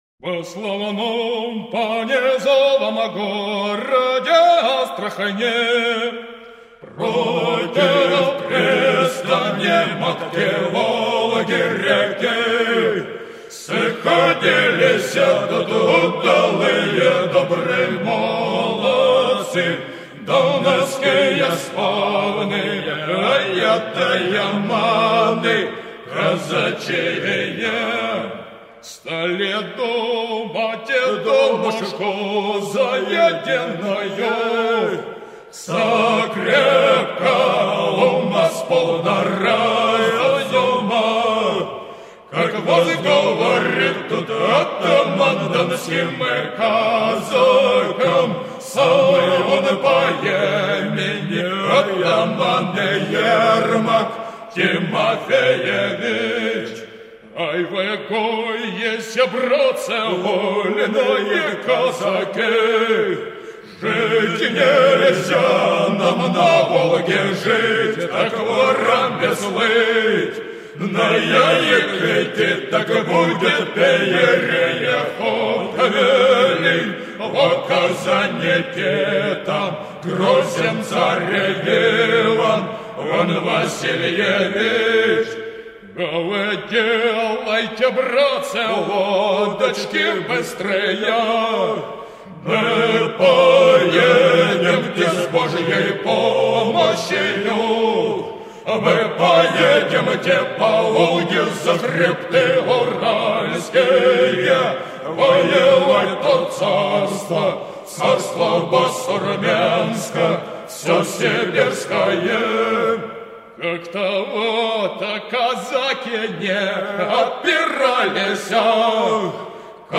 Стихиры Ивана Грозного